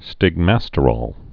(stĭg-măstə-rôl, -rōl)